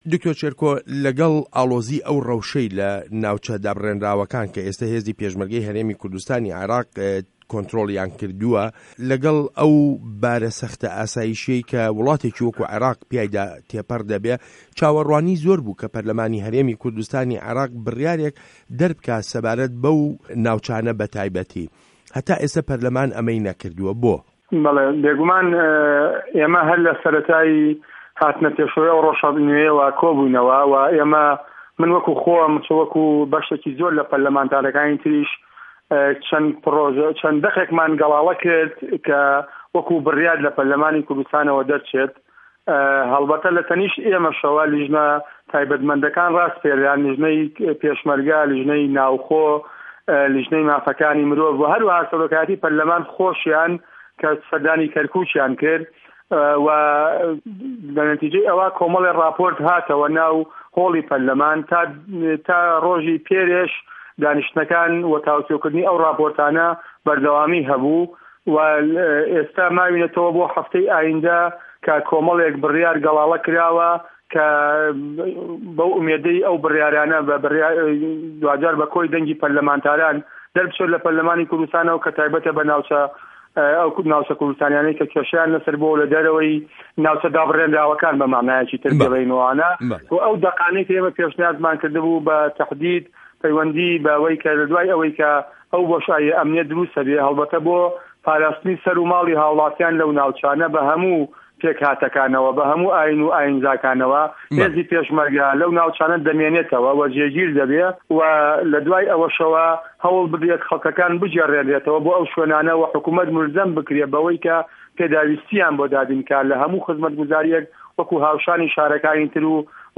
وتووێژ له‌گه‌ڵ دکتۆر شێرکۆ حه‌مه‌ ئه‌مین